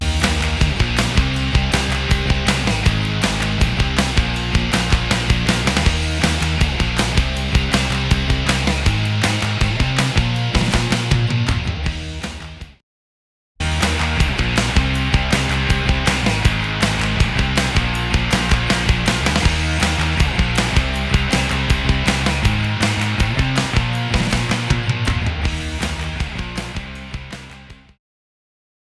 Звук гитара
В каком варианте лучше звучат гитары?